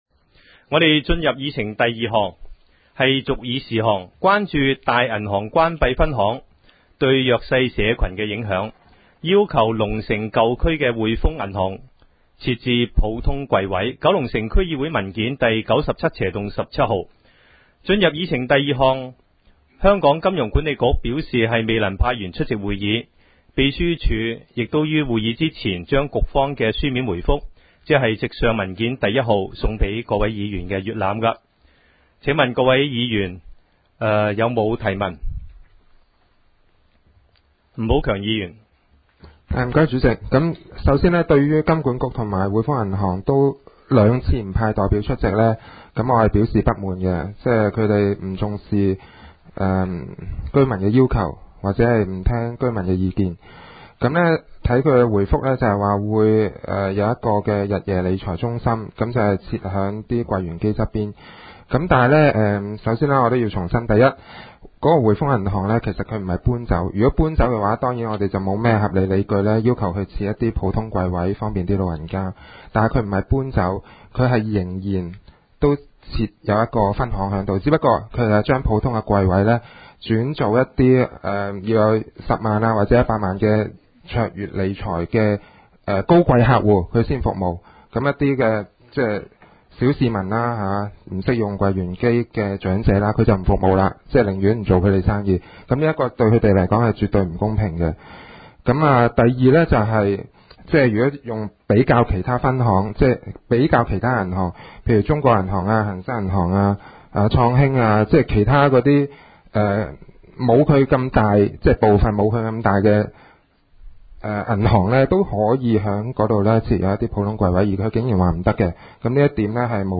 区议会大会的录音记录
九龙城民政事务处会议室